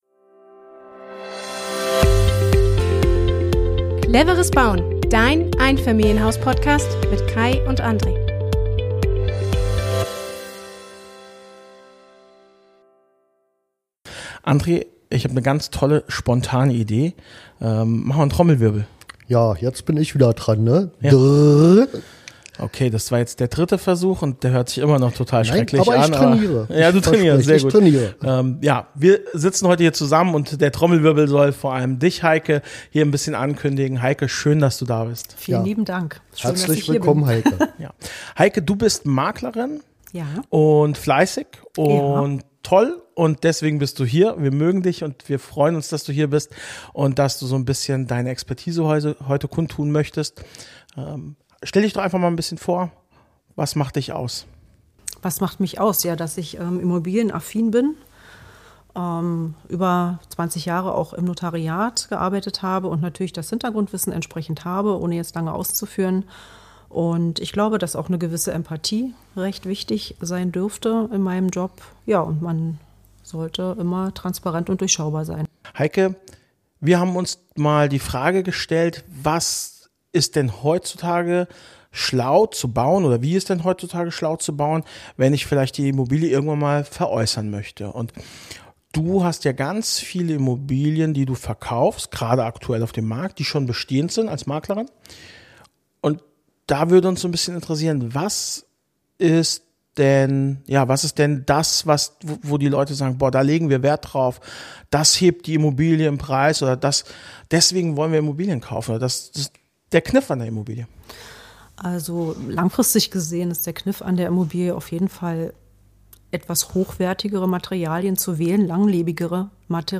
Im Talk